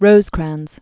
(rōzkrănz), William Starke 1819-1898.